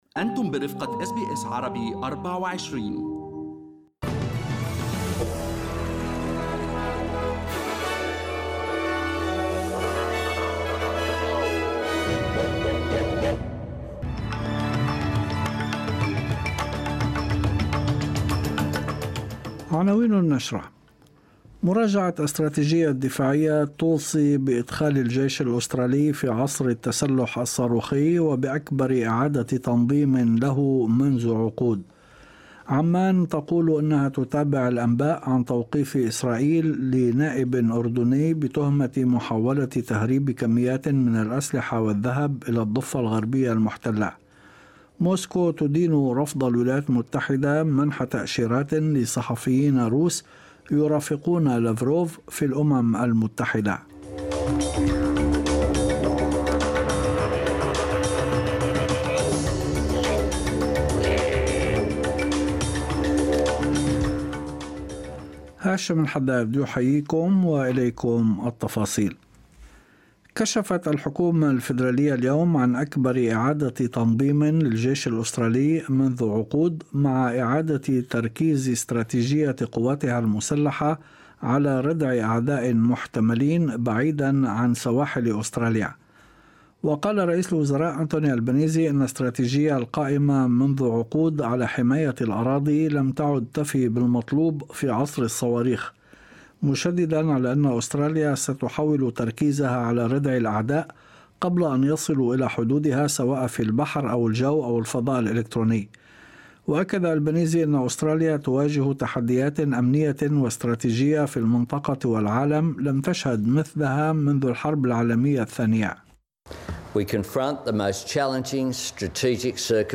نشرة أخبار المساء 24/4/2023